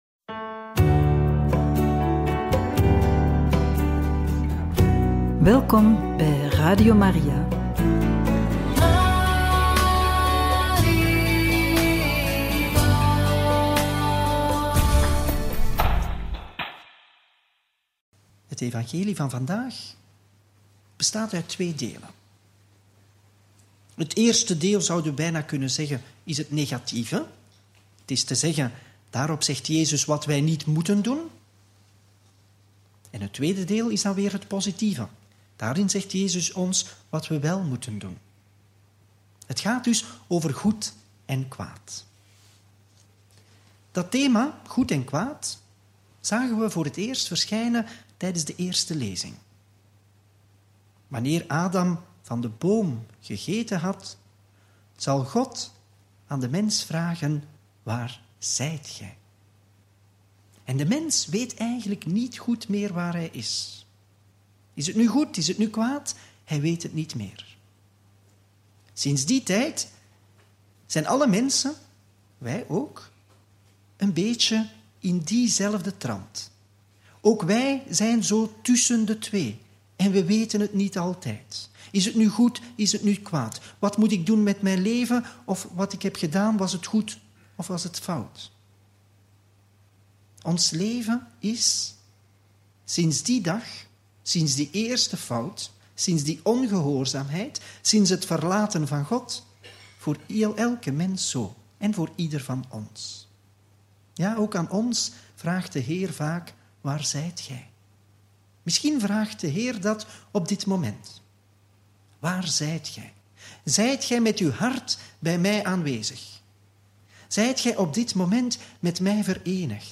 Herbeluister de homilie op de 10de zondag door het jaar – Radio Maria
herbeluister-de-homilie-op-de-10de-zondag-door-het-jaar.mp3